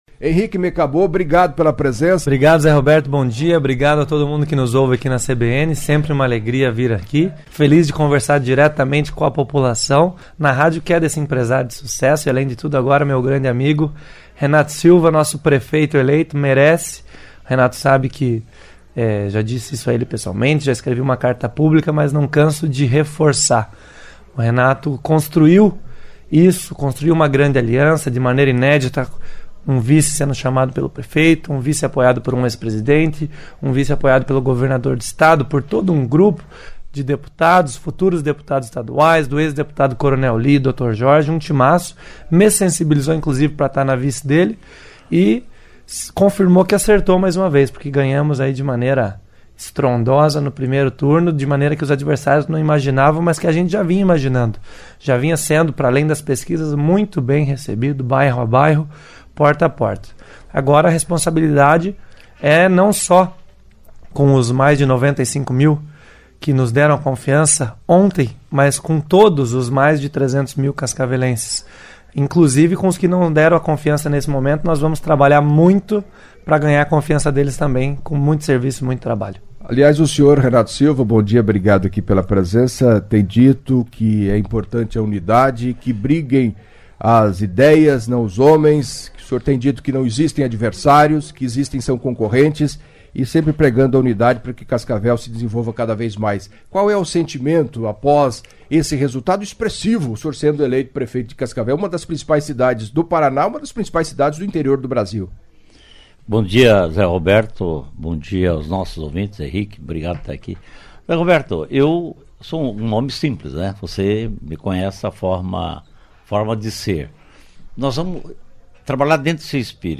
Em entrevista à CBN Cascavel nesta segunda-feira (07) Renato Silva (PL), prefeito eleito, acompanhado de Henrique Mecabo (Novo), vice-prefeito eleito, falou da vitória no primeiro turno, alcançou 95.168 votos, 56,41% dos votos válidos, e respondeu perguntas de ouvintes sobre o secretariado, tratamento com os servidores, licitações da coleta do lixo e transporte público, funcionamento do Hospital Municipal e reforçou que não existem mágoas diante dos ataques que teria sofrido durante a campanha.